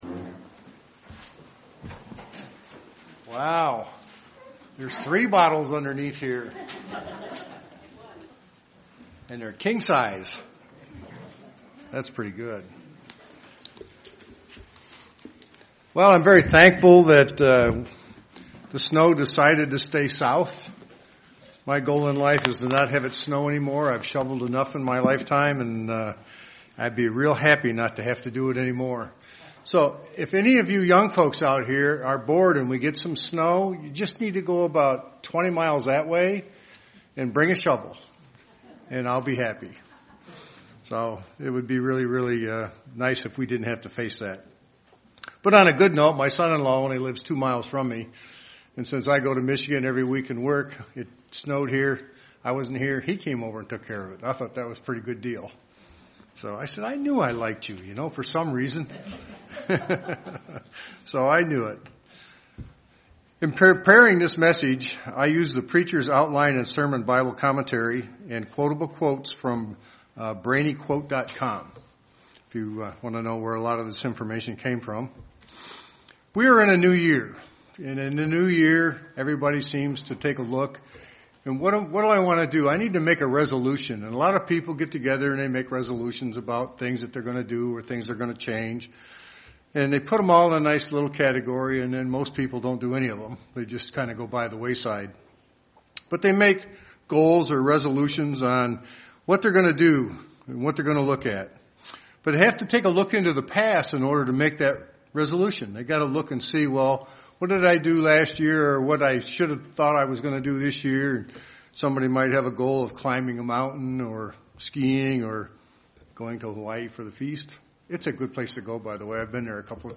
There are three types of vision: past, present, and future. This sermon will depict each of them and how we are to view them as Christians.